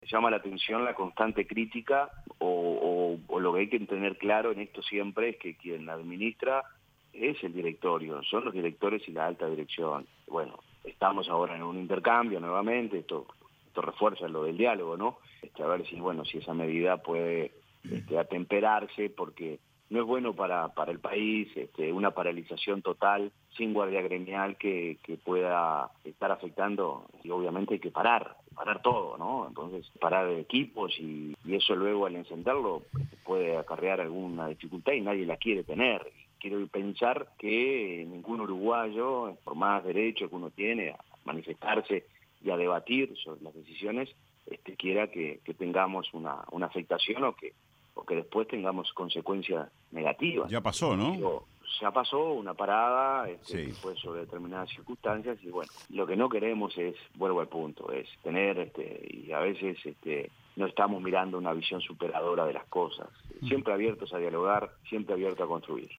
En entrevista en 970 Noticias, el vicepresidente de Ancap, Diego Durand, se refirió al paro que realizará Fancap el próximo 2 de marzo tras denuncias de persecución sindical y dijo que están en diálogo con el sindicato para tratar de «atemperar” la medida.
Escuche la entrevista completa de 970 Noticias con el vicepresidente de Ancap, Diego Durand: